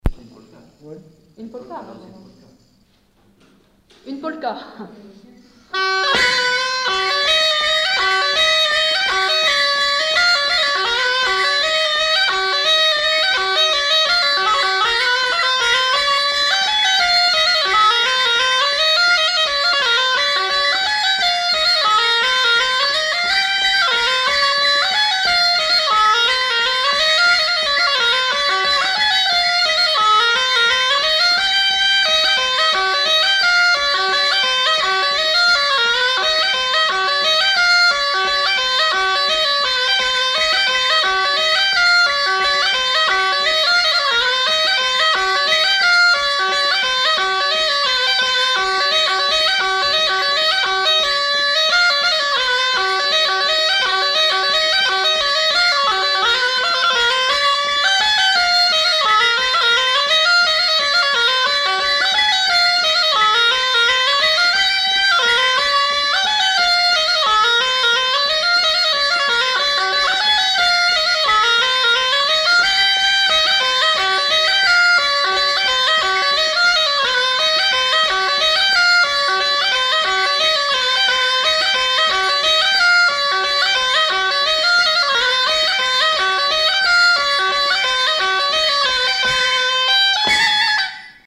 Lieu : Marvejols
Genre : morceau instrumental
Instrument de musique : cabrette ; grelot
Danse : polka